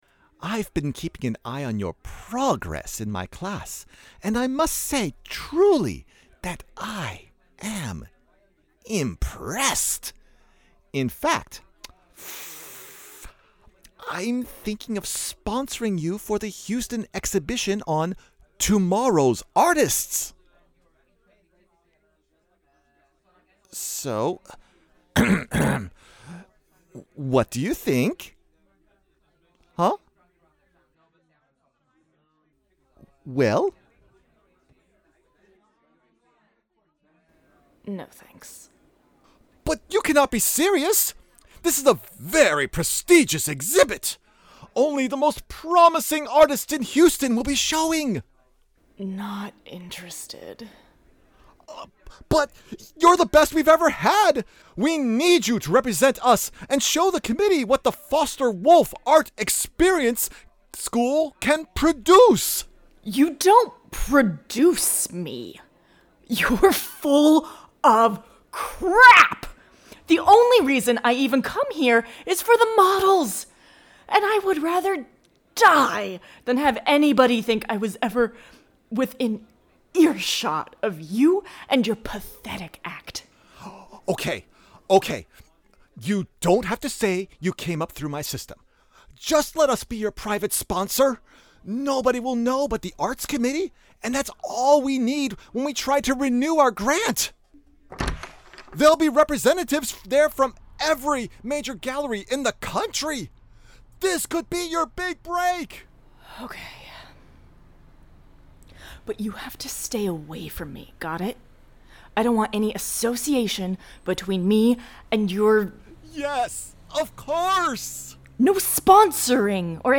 Strangers In Paradise – The Audio Drama – Book 7 – Episode 4 – Two True Freaks
The Ocadecagonagon Theater Group